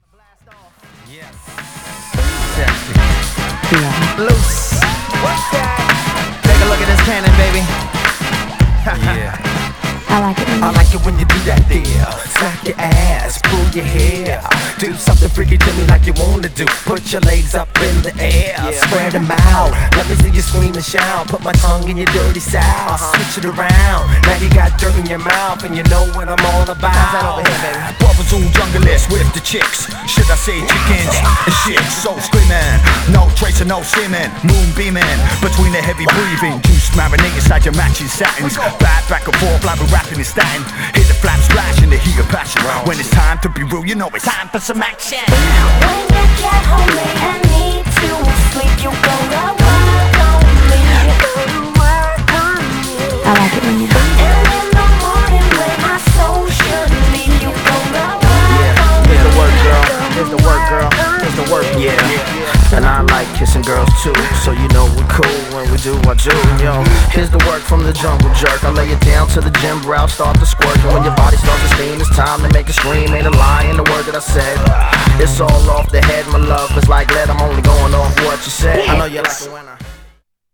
Styl: Hip Hop, House, Breaks/Breakbeat